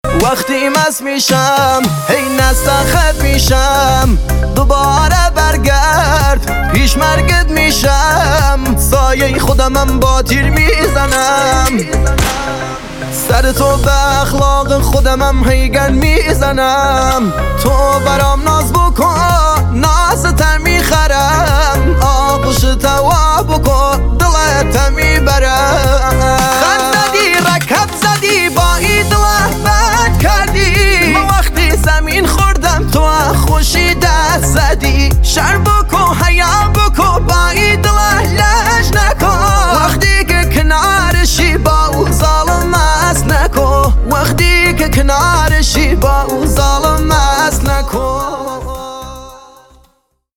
جدید شاد و پرانرژی